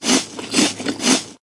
怪物咀嚼
描述：口香糖产生的怪物咀嚼声，有效果。
Tag: 咀嚼 进食 怪物